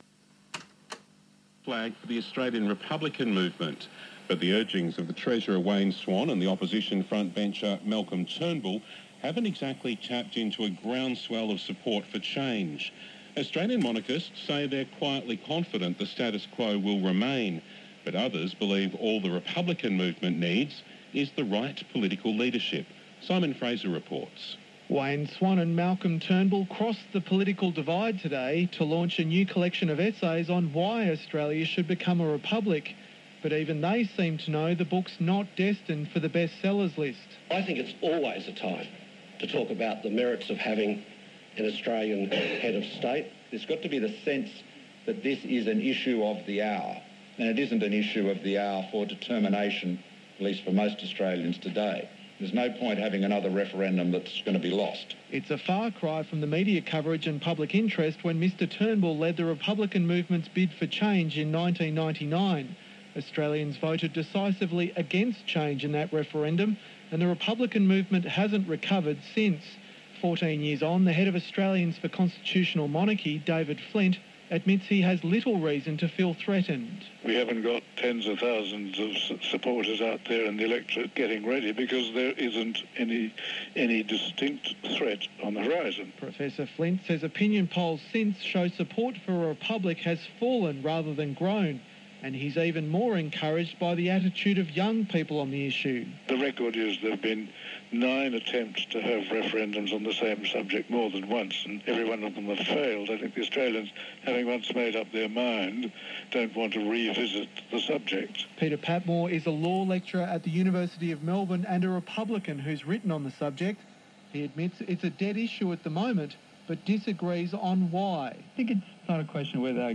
ABC Report: The Swan-Turnbull Republican Alliance